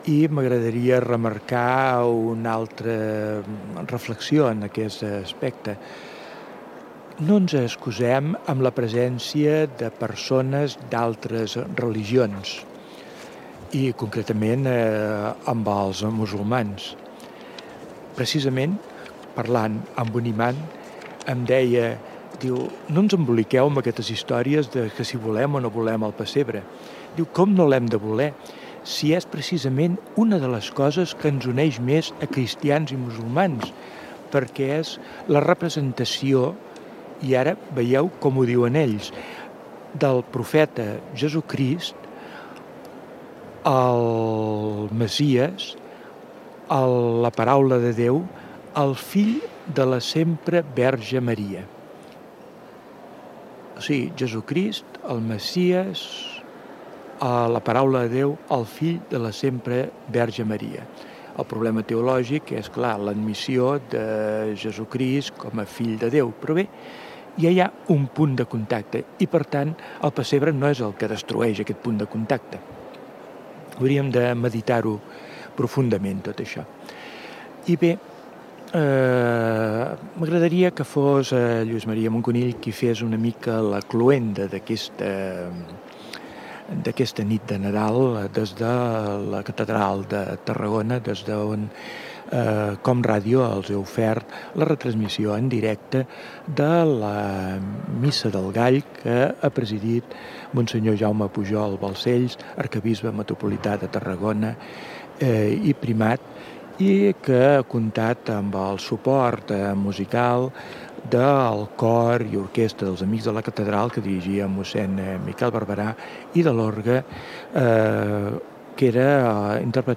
Final de la transmissió de la missa del gall, des de la Catedral de Tarragona. La respresentació del pessebre. Persones que han intervingut en la celebració. Comiat dels comentaristes, careta de sortida del programa i indicatiu de l'emissora.
Religió